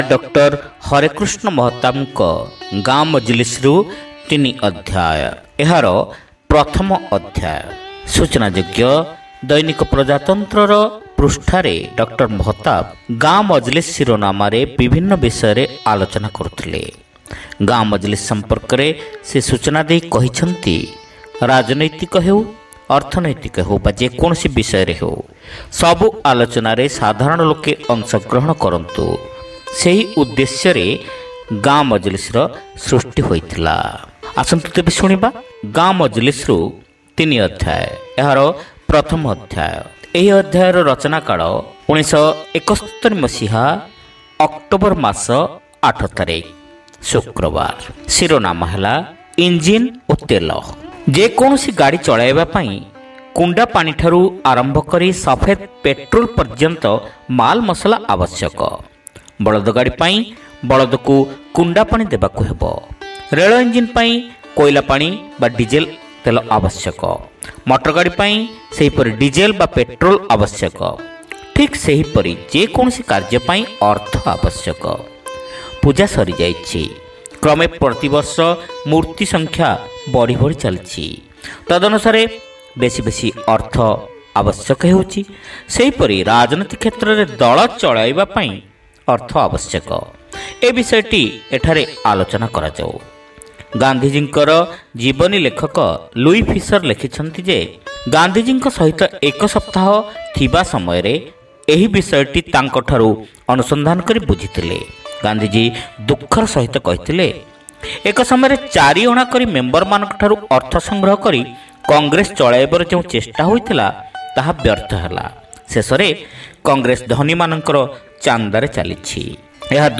Audio Story : Engine O Tela - Gaon Majlis